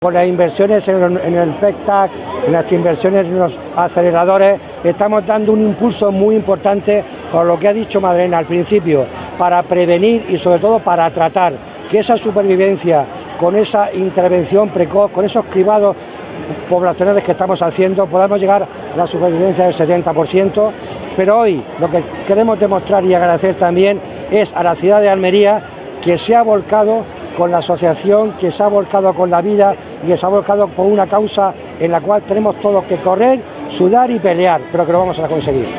Juan-de-la-Cruz-Belmonte-Delegado-Salud-Carrera-contra-el-Cancer.wav